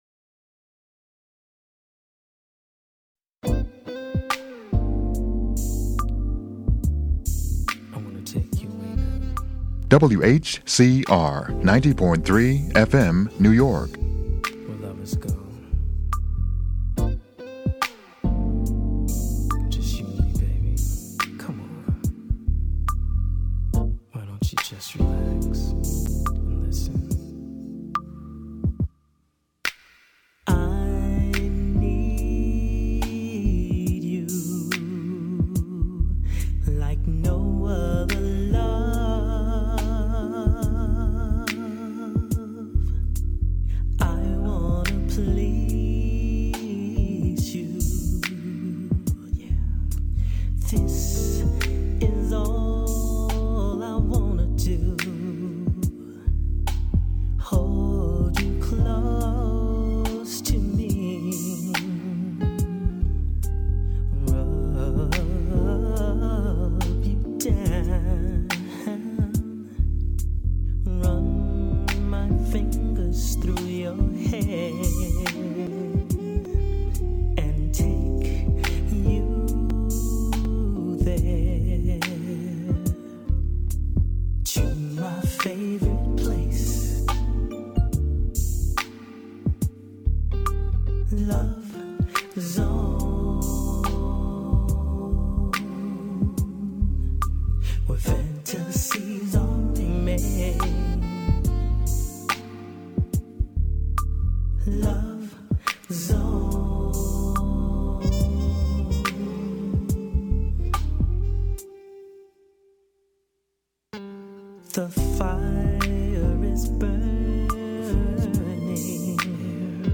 Old School, Classic R&B, and Oldie But Goodie